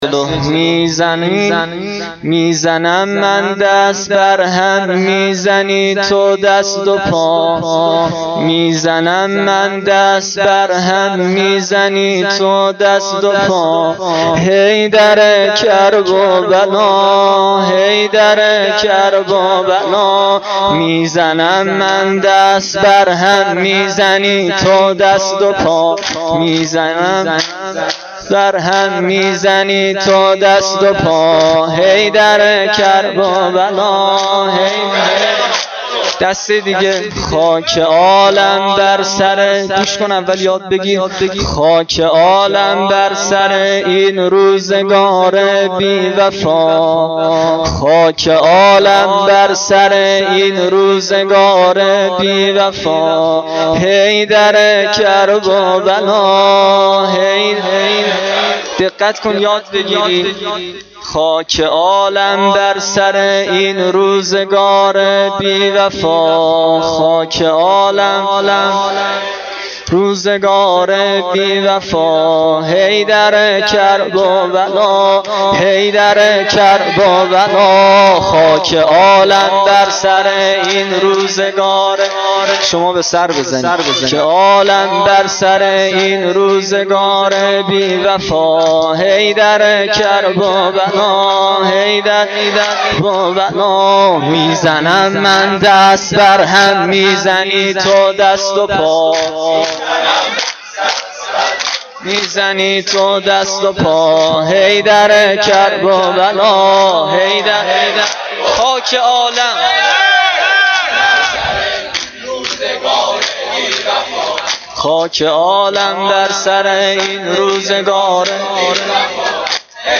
دودمه شب هشتم محرم1393